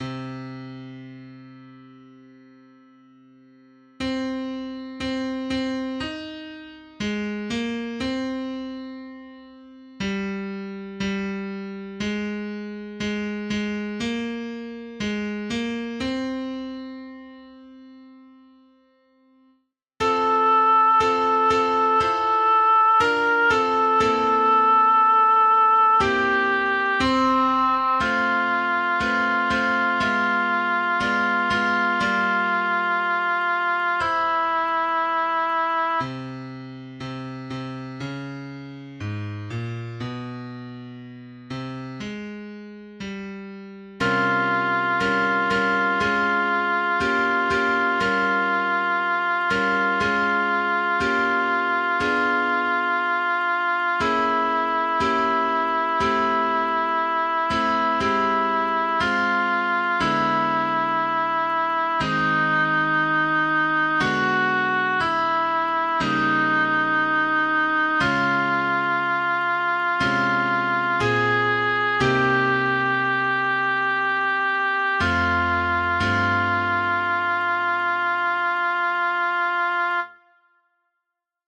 Sopran 2
stemning-alfven-sopran2.mp3